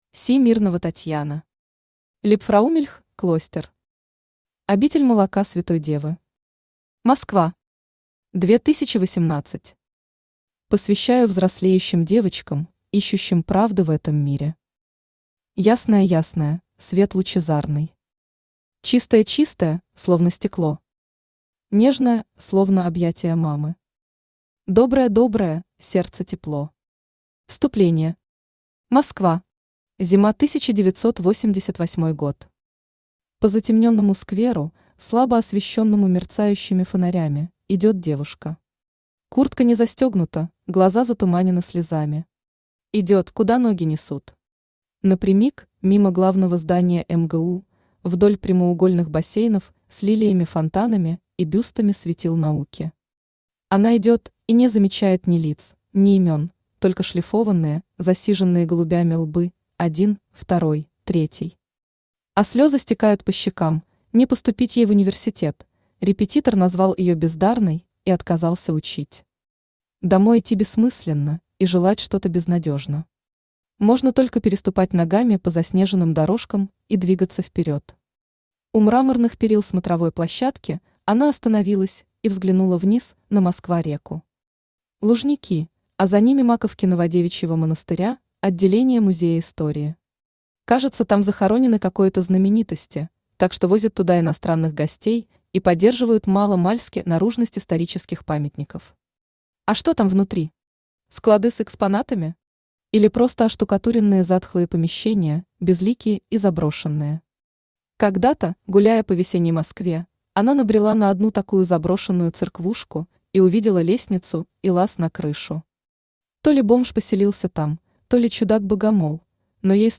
Аудиокнига Либфраумильх клостер. Обитель молока Святой Девы | Библиотека аудиокниг
Обитель молока Святой Девы Автор Татьяна Андреевна Смирнова Читает аудиокнигу Авточтец ЛитРес.